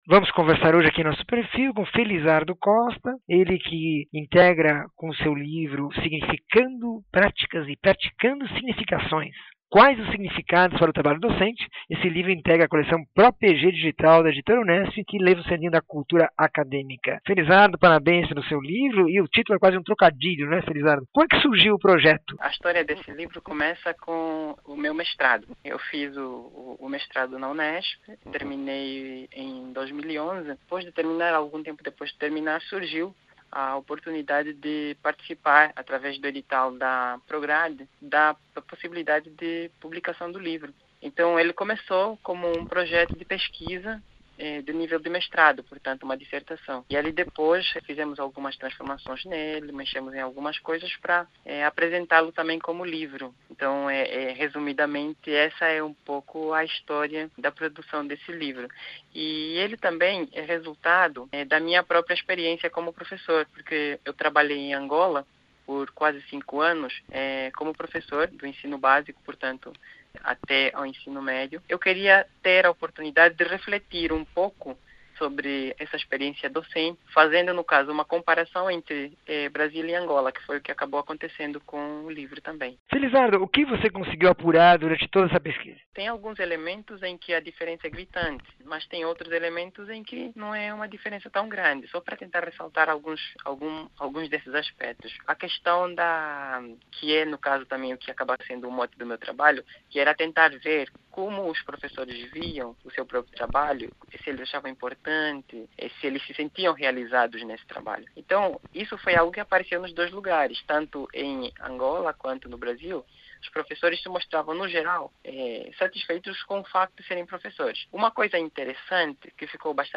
entrevista 2177